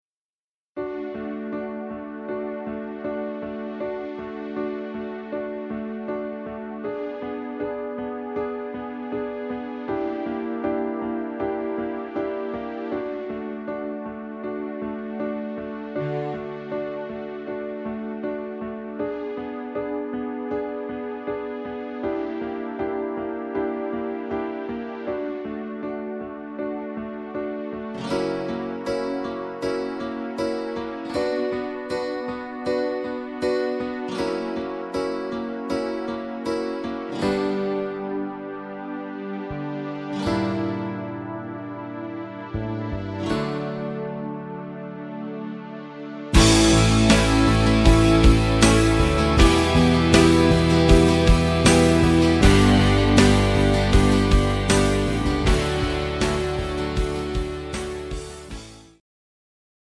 Rhythmus  Ballade
Art  Italienisch, Pop